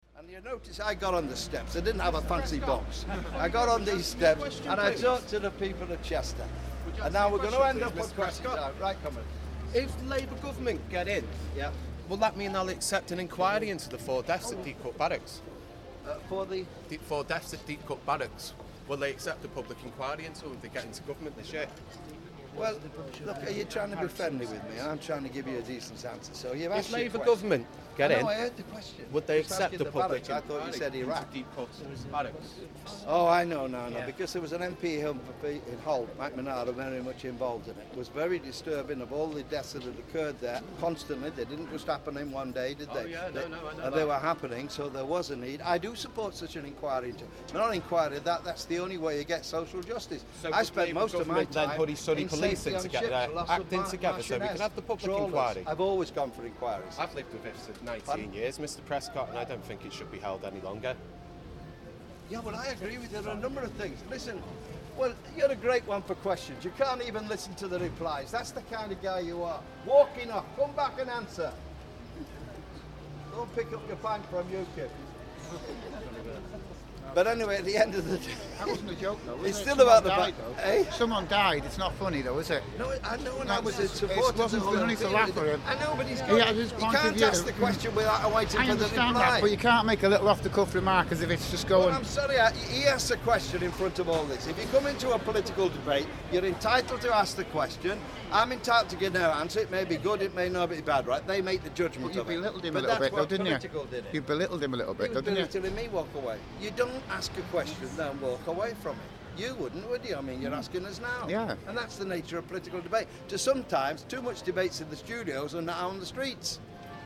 John Prescott getting into arguments with passers by at The Cross in Chester.